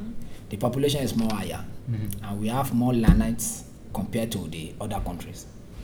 S3 = Nigerian male S4 = Pakistani male
Intended Words: learneds Heard as: land rights